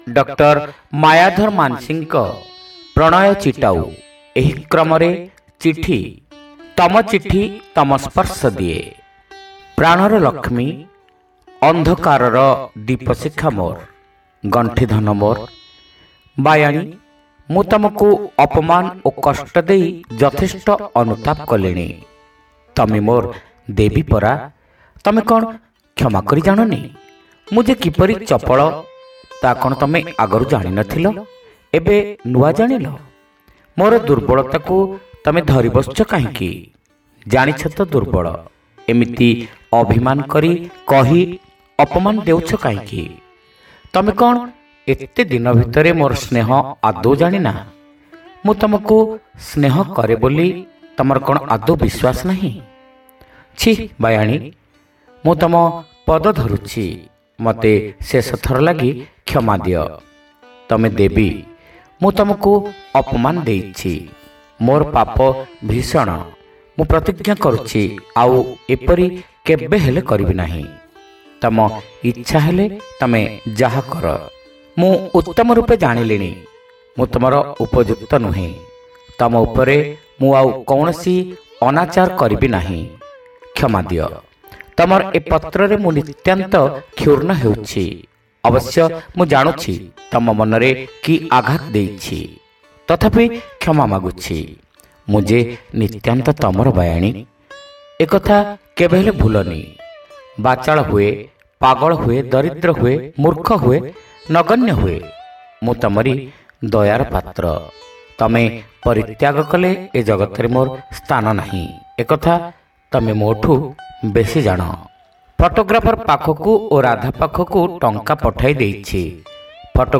ଶ୍ରାବ୍ୟ ଗଳ୍ପ : ତମ ଚିଠି ତମ ସ୍ପର୍ଶ ଦିଏ